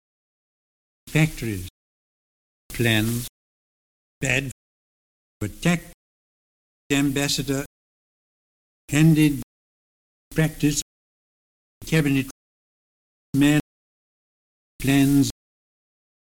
All the recordings have been subjected to lossy MP3 compression at some time during their lives.
Listen to closer and open TRAP (400-700Hz) by Neville Chamberlain, arranged from high F1 to low F1 from open [æ] to close [æ] (Figure 3):